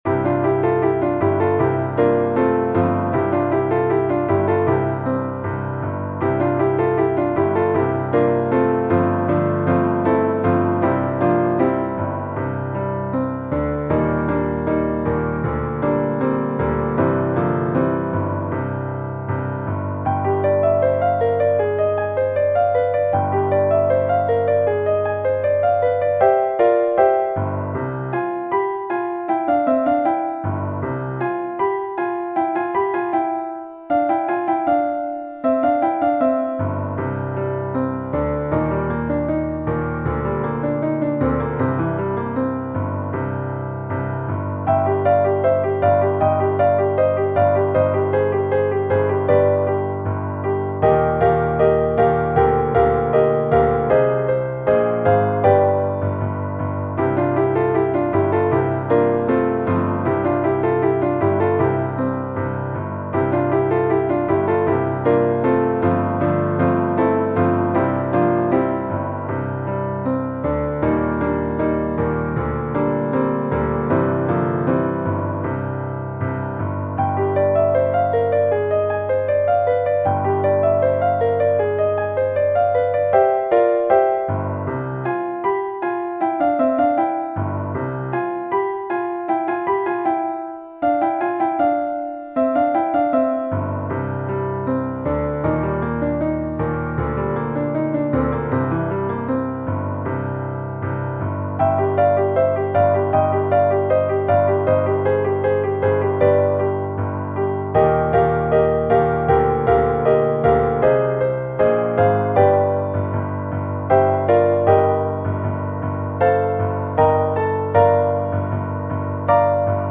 Contemporary Classical, Christmas